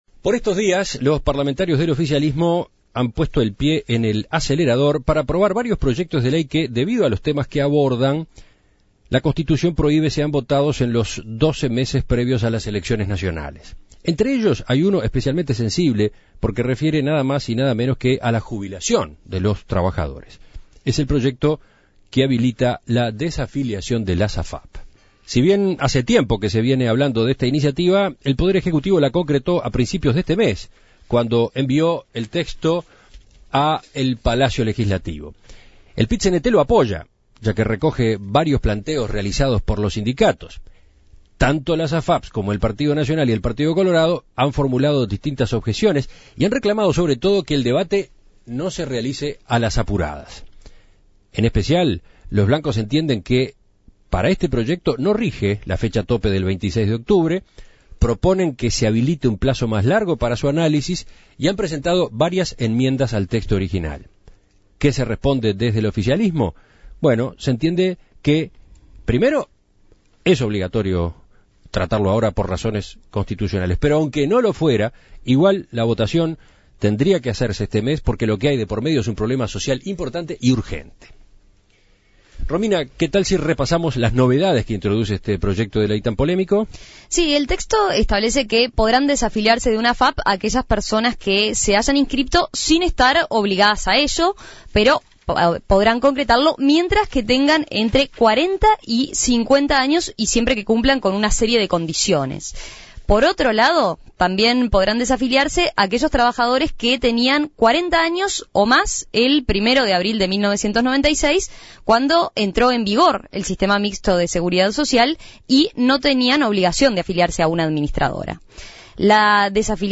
Entrevistas Gobierno defiende "negociación" tras proyecto de desafiliación de las AFAP y éstas critican que se legisle "a contrarreloj" (audio) Imprimir A- A A+ El Parlamento se apresta a votar esta semana el proyecto de ley impulsado por el Poder Ejecutivo que habilita la desafiliación de las AFAP.